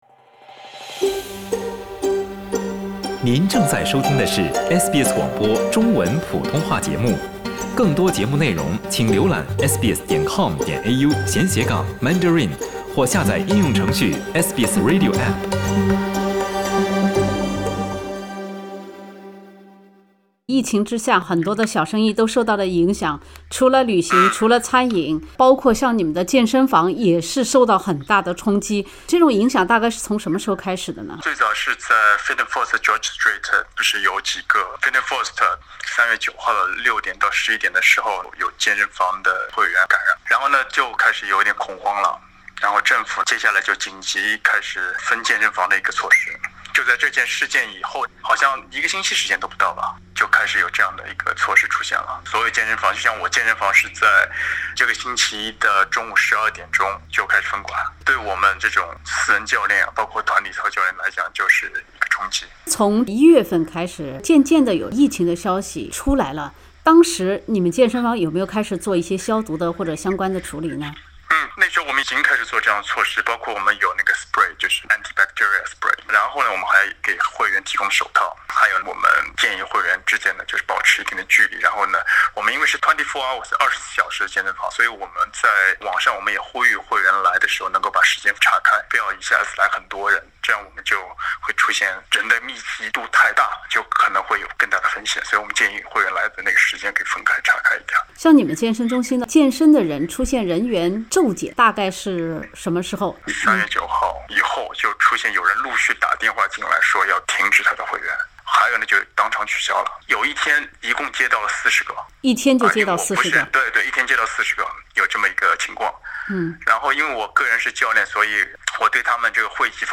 他现在已经准备跟银行重新谈一下还贷问题。请点击上图收听采访。